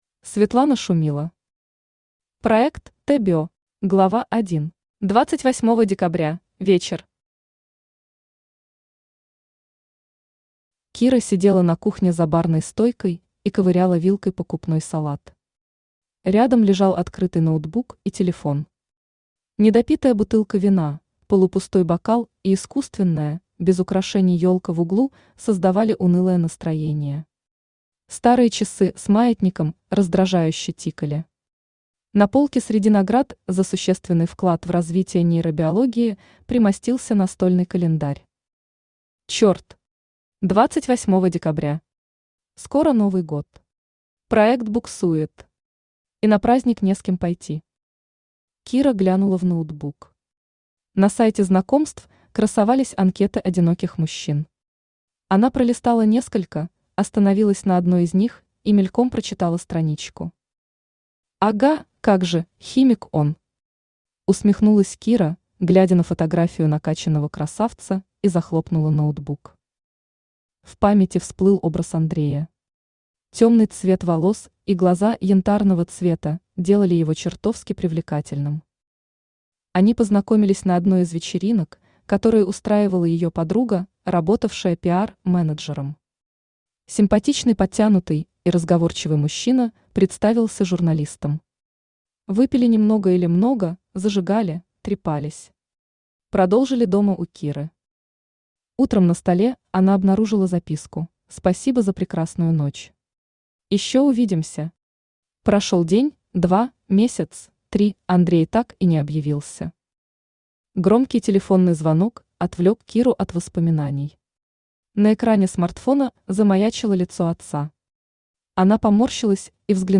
Аудиокнига Проект «Т-био» | Библиотека аудиокниг
Aудиокнига Проект «Т-био» Автор Светлана Шумила Читает аудиокнигу Авточтец ЛитРес.